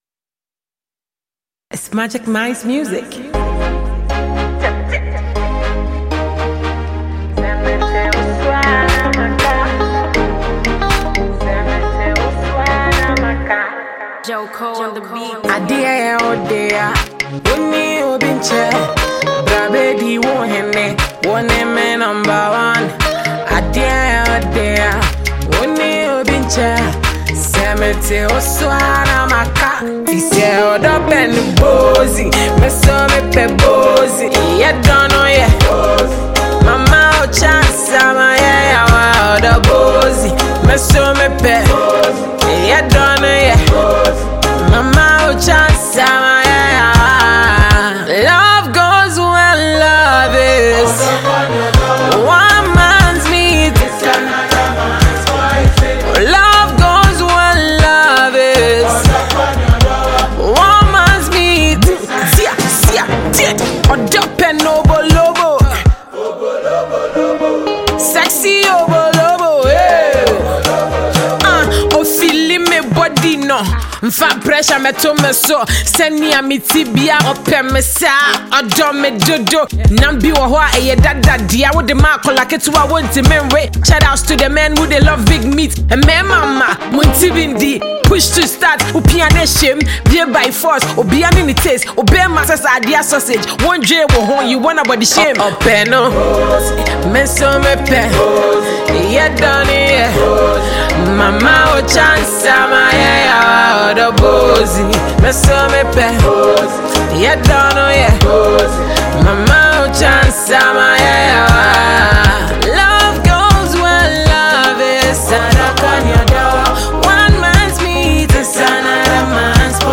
Ghanaian female rapper and songwriter